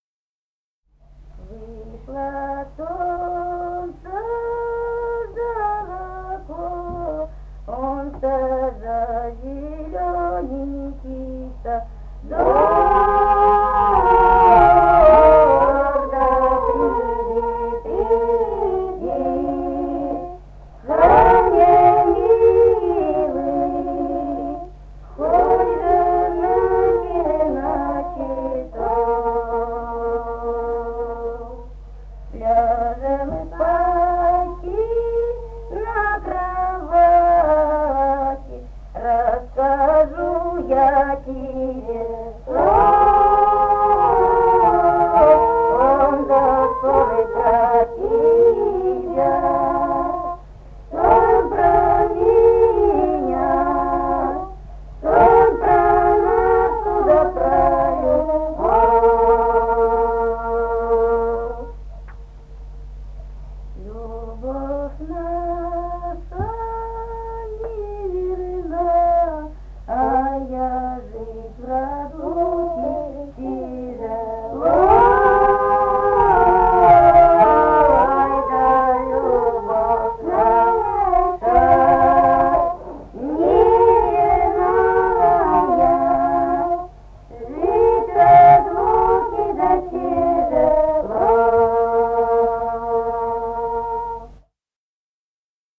Русские народные песни Красноярского края.
«Вышло солнце за оконце» (лирическая). с. Тасеево Тасеевского района. Пела группа колхозниц